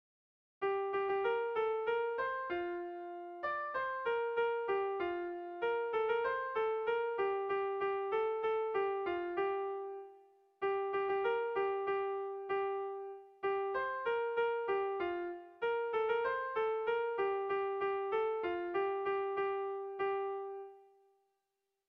Tragikoa
Zortziko txikia (hg) / Lau puntuko txikia (ip)
A1B1A2B2